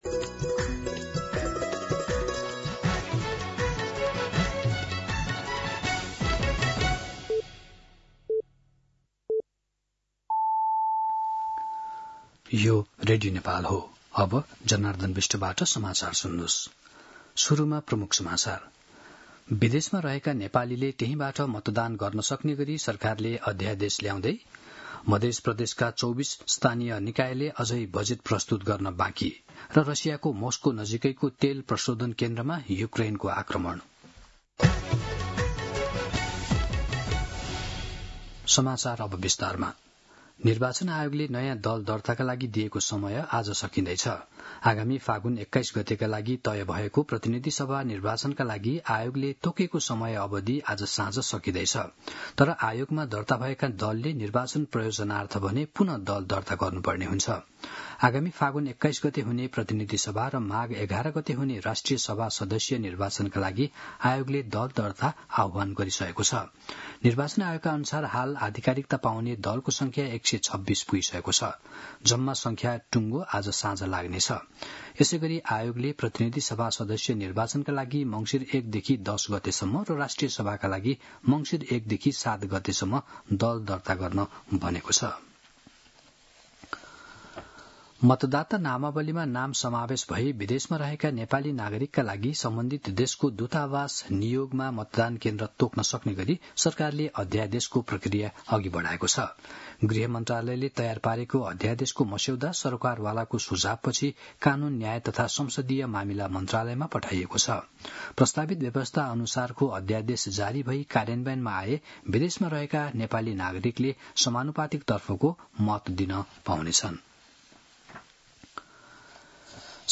दिउँसो ३ बजेको नेपाली समाचार : ३० कार्तिक , २०८२